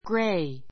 gray A1 ɡréi グ レ イ 形容詞 比較級 grayer ɡréiə r グ レ イア 最上級 grayest ɡréiist グ レ イエ スト ❶ 灰色の , ねずみ色の, グレーの gray eyes gray eyes 灰色の目 His necktie was dark gray.